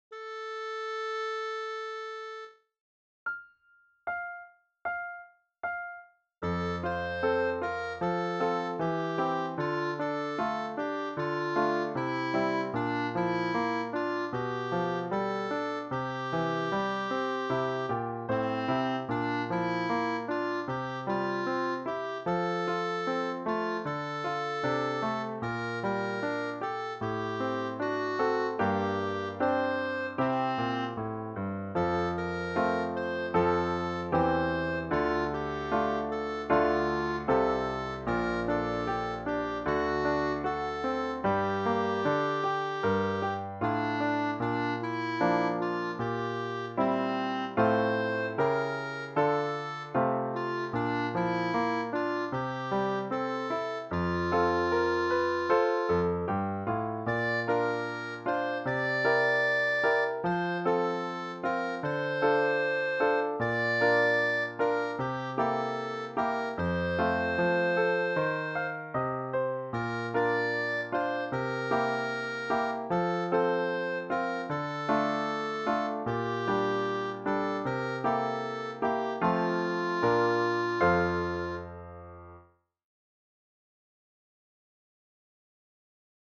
Ici, on pourra acceder à une version accompagnée des mélodies et chansons apprises lors de nos cours.
J’ai composé cette mélodie pour travailler la syncope de noire (ti-taa-ti).